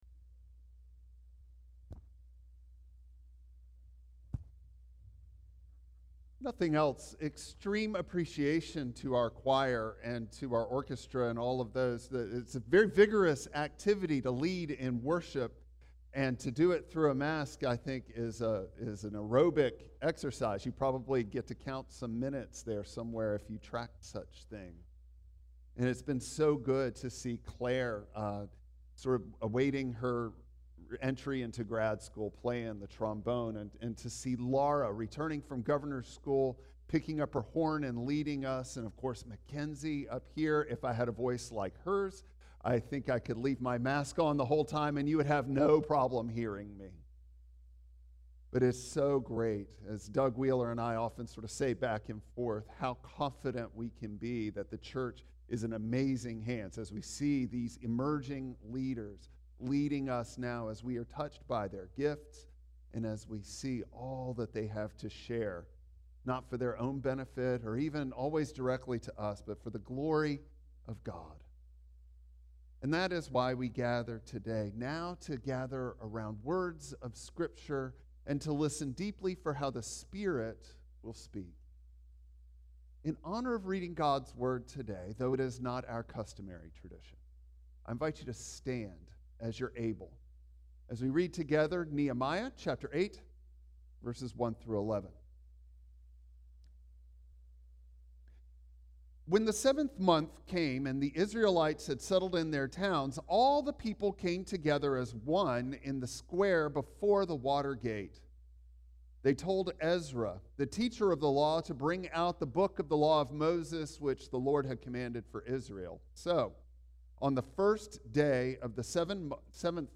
Passage: Nehemiah 8:1-11 Service Type: Traditional Service Bible Text